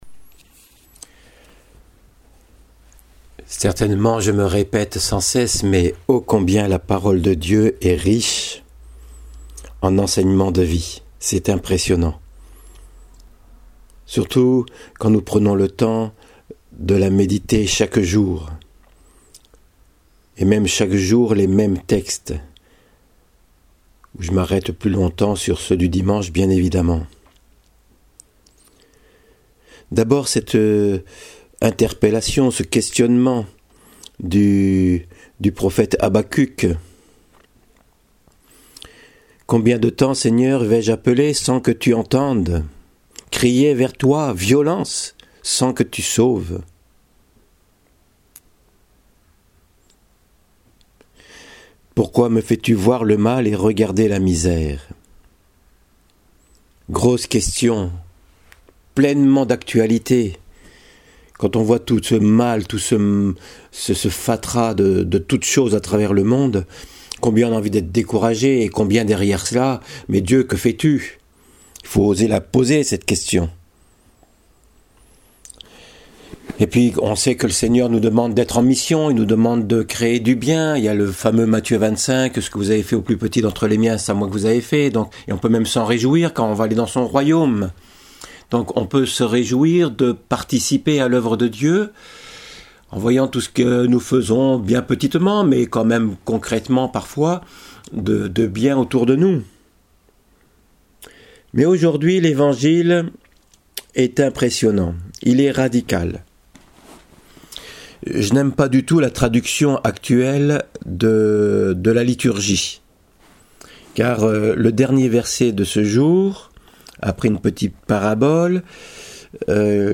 En ce 27° dimanche du temps ordinaire, eh oui les semaines passent… voici un évangile que peu de personnes n’apprécient car il nous est révélé que nous sommes des serviteurs inutiles. oh làlà ça fout un coup à notre égo, mais c’est bien là notre vrai combat. Voici l’homélie en audio: => Etre des serviteurs inutiles